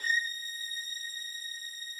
strings_082.wav